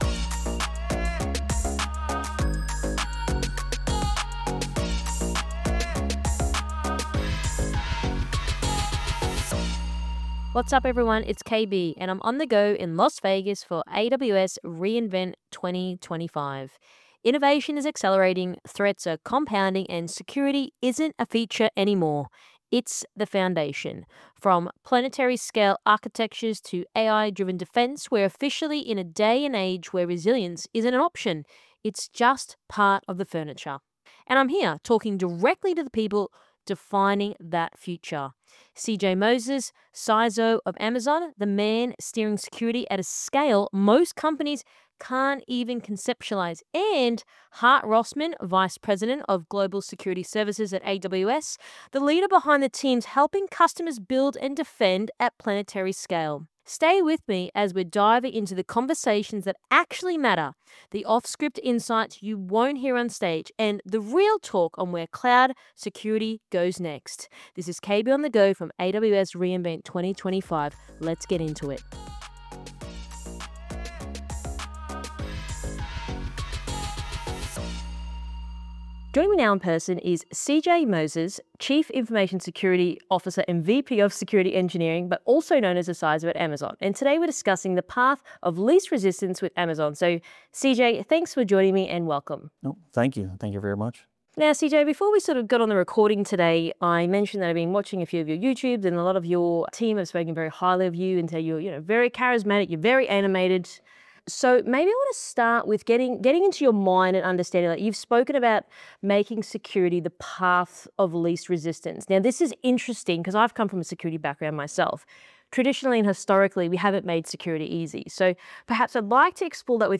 From AWS re:Invent 2025